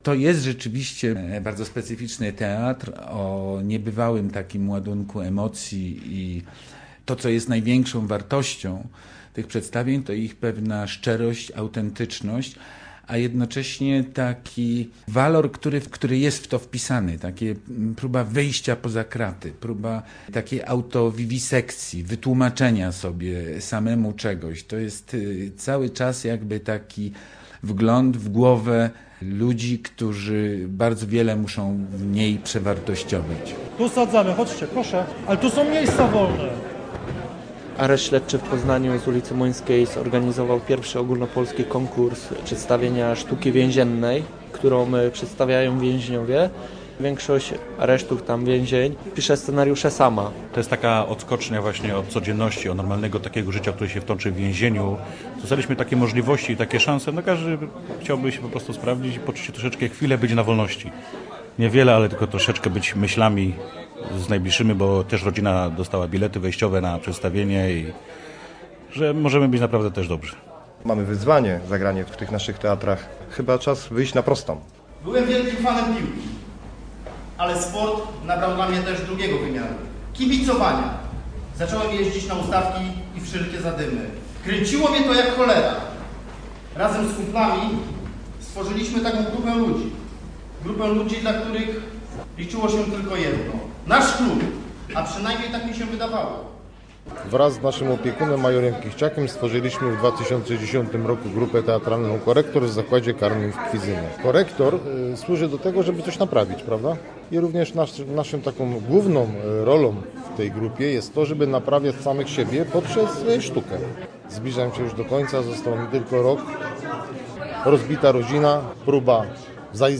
Czas na prostą - reportaż